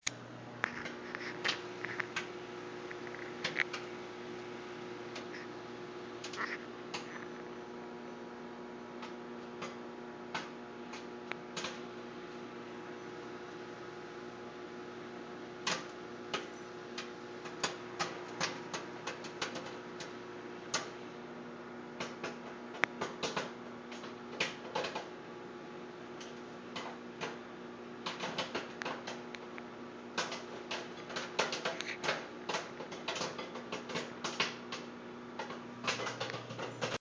field recording
making popcorn location: kitchen sounds heard: popcorn popping, hum of microwave
sounds heard: popcorn popping, hum of microwave
making-popcorn.mp3